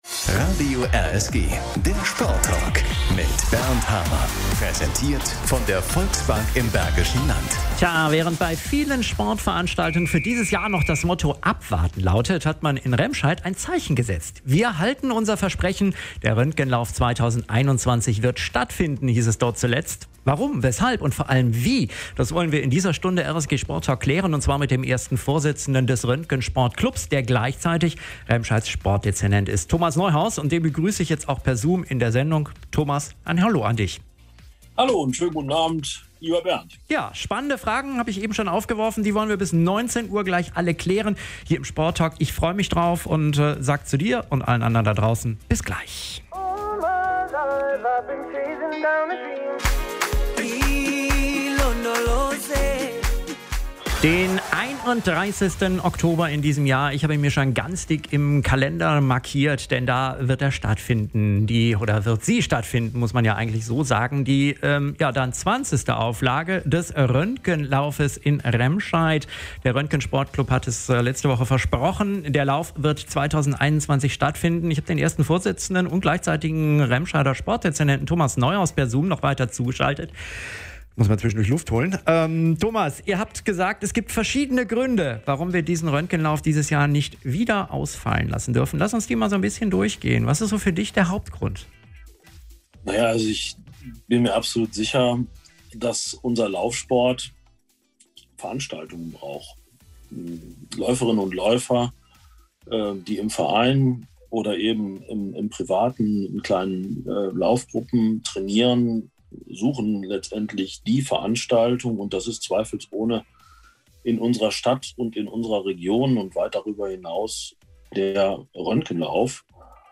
Oktober (Sendung vom 01.03.2021): Veröffentlicht: Montag, 01.03.2021 18:13 Anzeige RSG-Sporttalk Röntgenlauf 2021 play_circle Abspielen download Anzeige